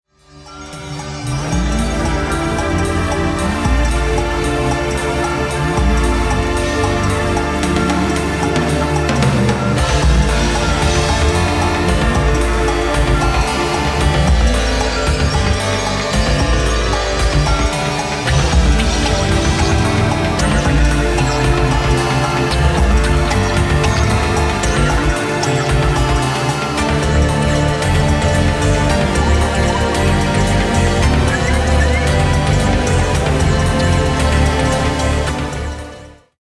Electronix House Ambient